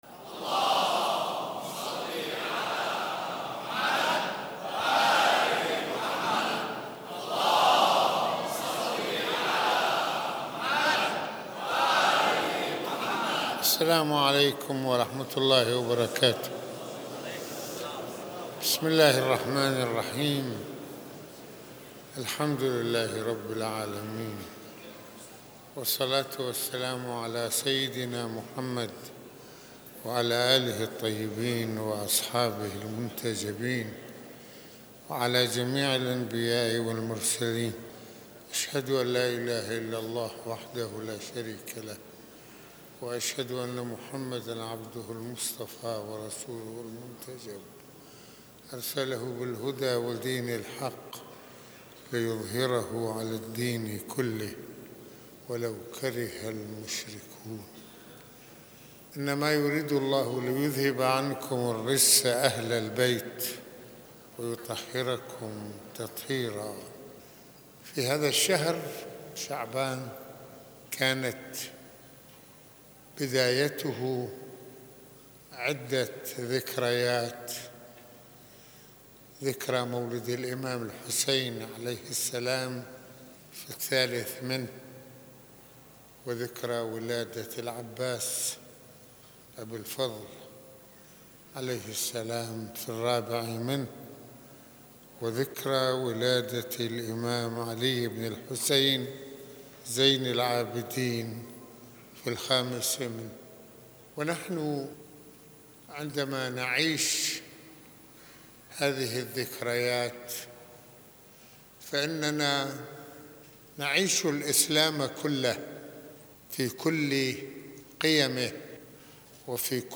خطبة الجمعة المكان : مسجد الإمامين الحسنين (ع)